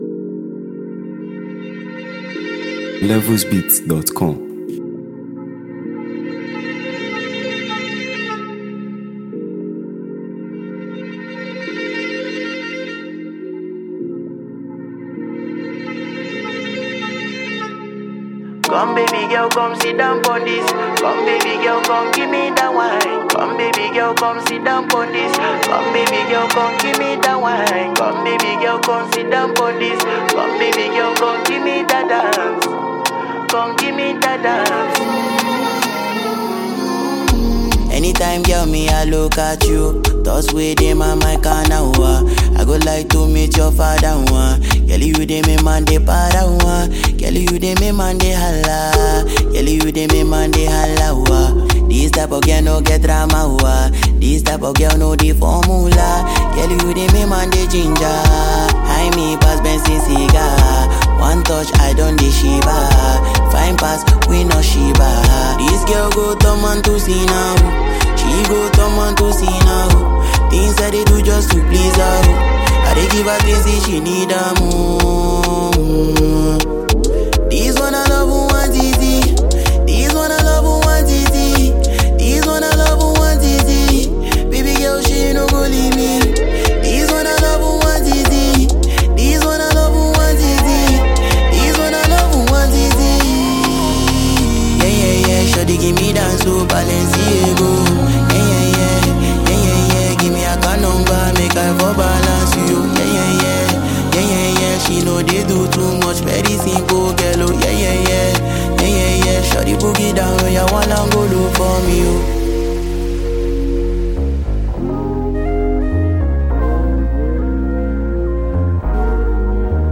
heartfelt and melodious track